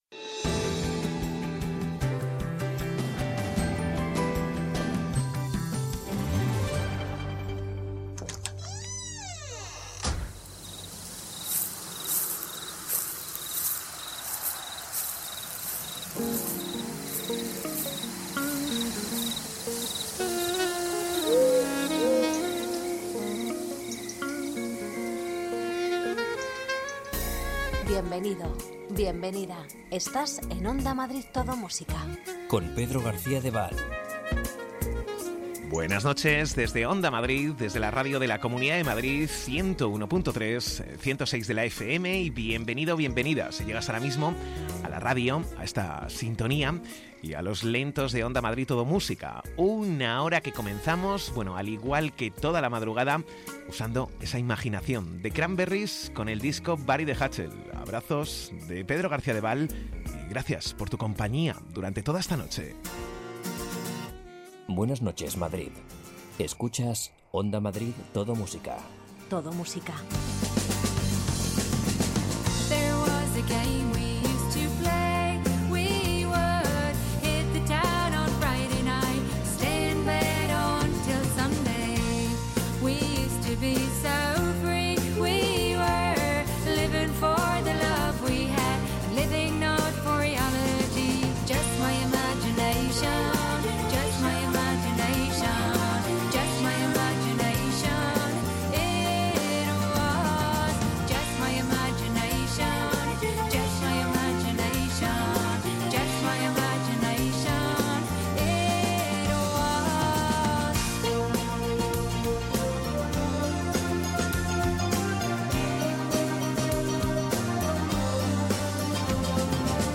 De lunes a viernes madrugadas de lentos, ritmo en el sábado noche, y tardes del fin de semana con muchos recuerdos y curiosidades.